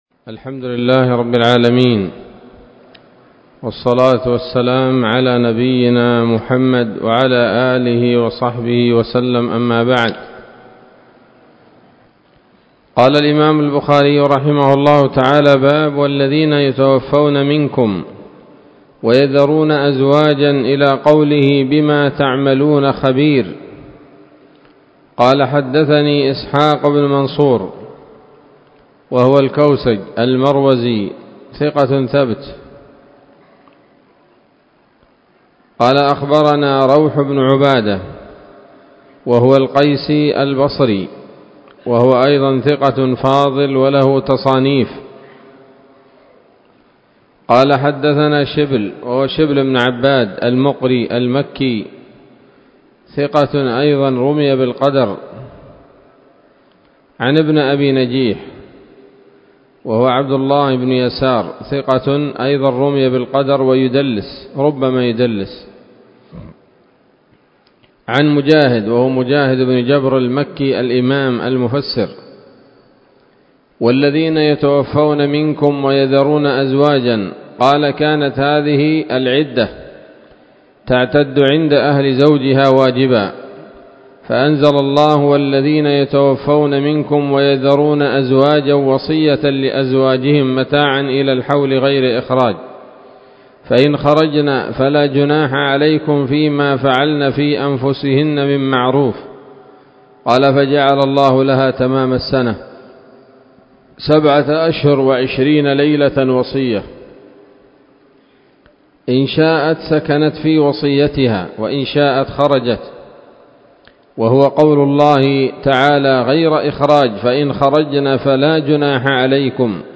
الدرس الثامن والثلاثون من كتاب الطلاق من صحيح الإمام البخاري